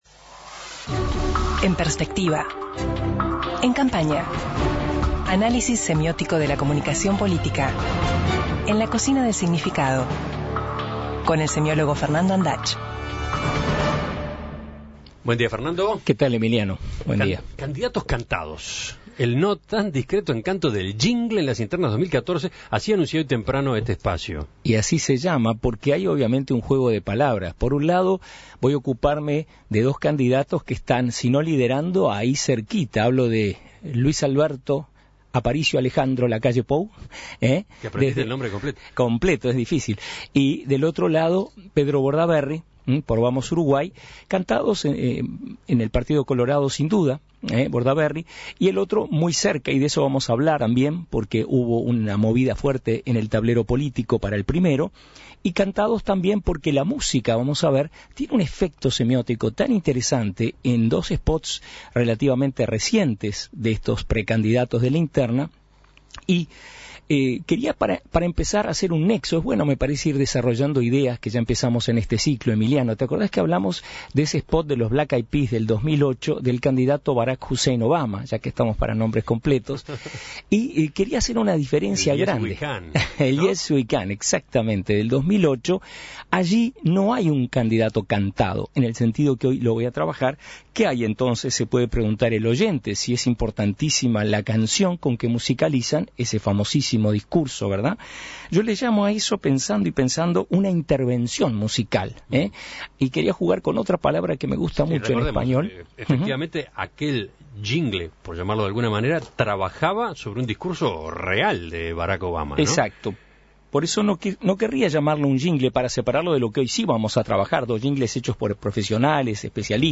Análisis semiótico de la comunicación política. Con el semiólogo